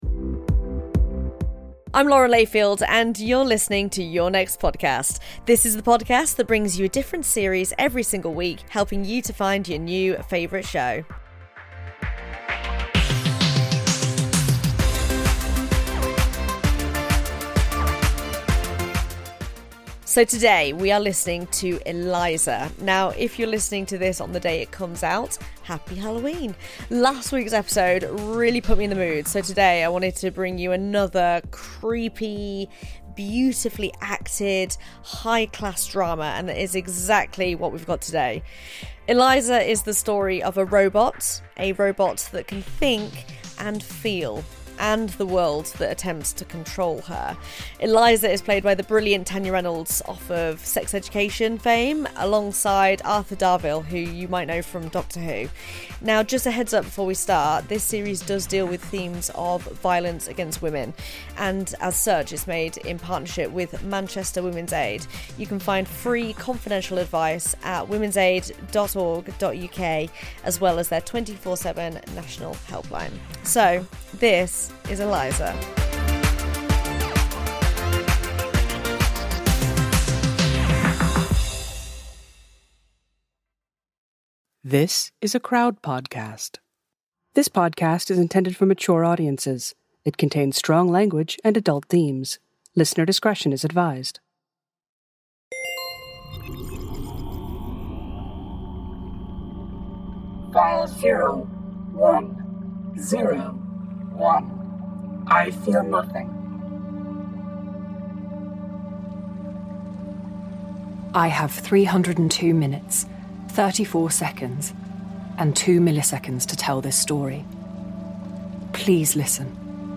Lauren Layfield introduces Eliza on the series recommendation show Your Next Podcast.
Tanya Reynolds (Sex Education) stars in a dark, near-future science fiction fairytale about a robot who can feel, and the world that attempts to control her. When a robot called Eliza falls in love with Him (Arthur Darvill, Doctor Who), they work together to make her fully sentient.